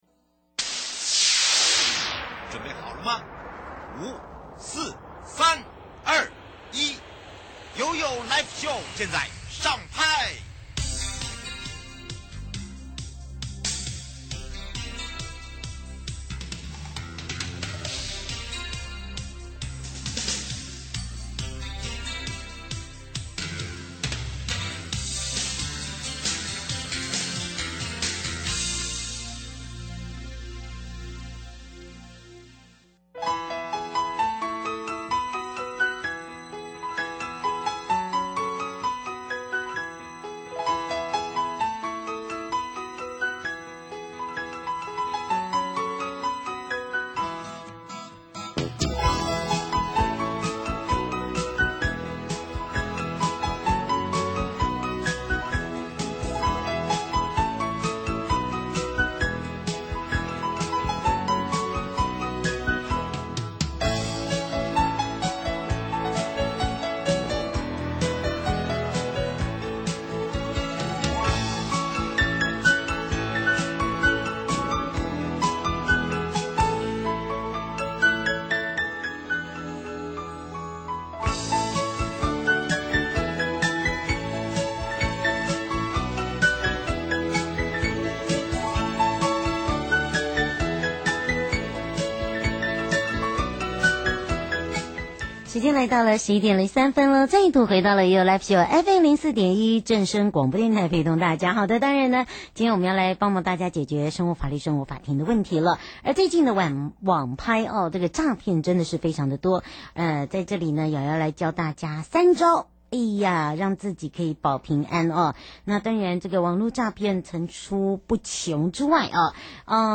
受訪者： 台北地檢許祥珍主任檢察官 節目內容： 1. 偽藥風波民眾如何發現偽藥？涉及什麼刑事責任？ 2. 一次毒萬骨枯拒絕毒害這麼做